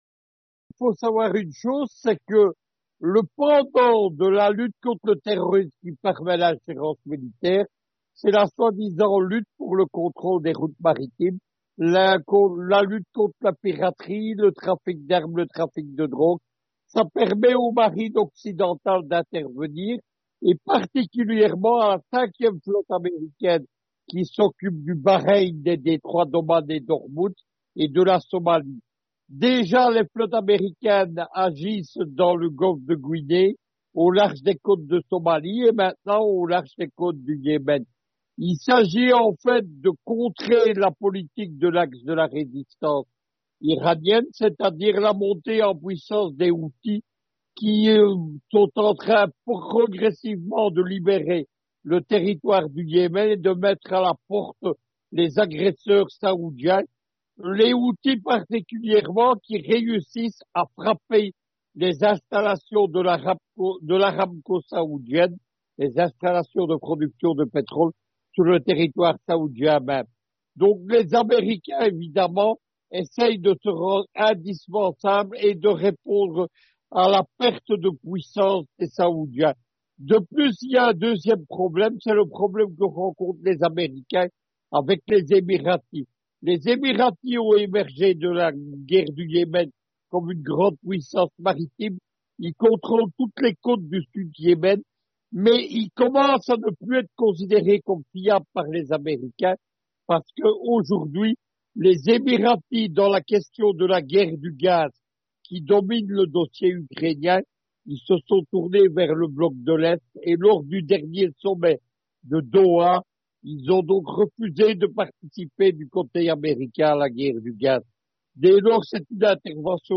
géopoliticien, s'exprime sur ce sujet.